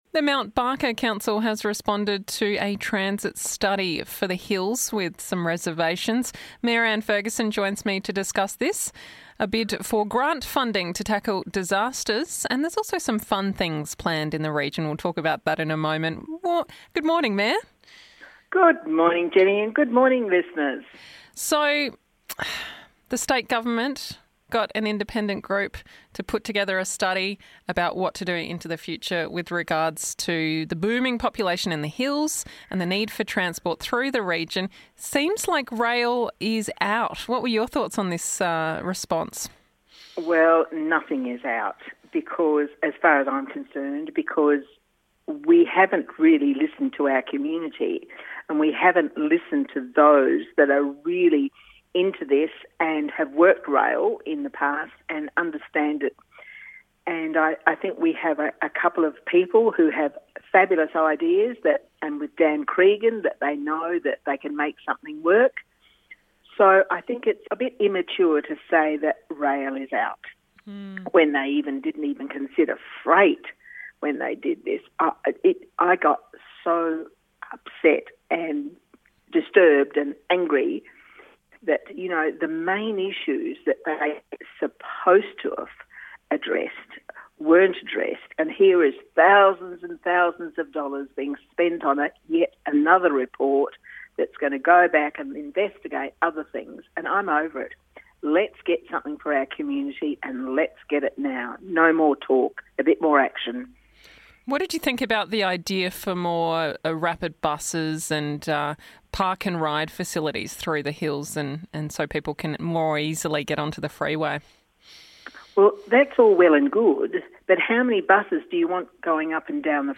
1512: Mt Barker Mayor Talks Transport, Bushfire Funds & Upcoming Events